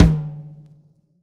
drum-hitclap.wav